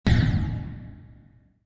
game_over.wav